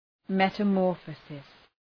Shkrimi fonetik{,metə’mɔ:rfəsıs}
metamorphosis.mp3